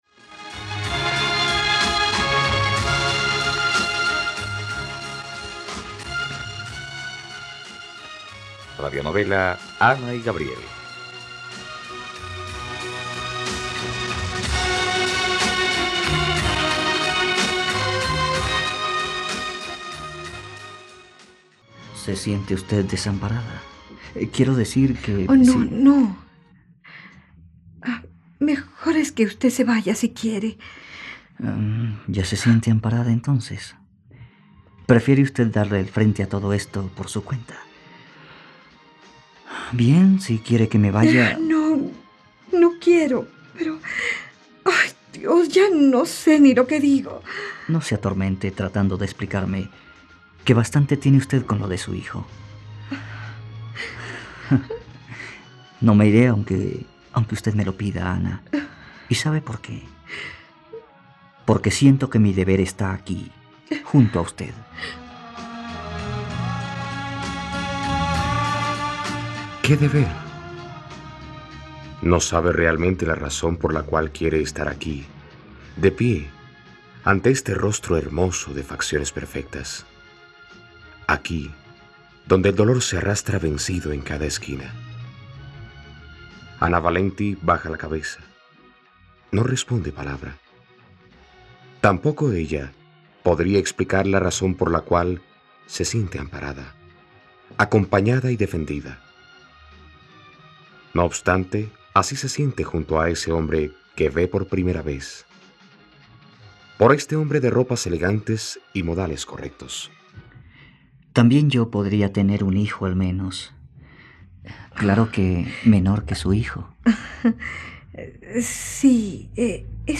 ..Radionovela. Escucha ahora el octavo capítulo de la historia de amor de Ana y Gabriel en la plataforma de streaming de los colombianos: RTVCPlay.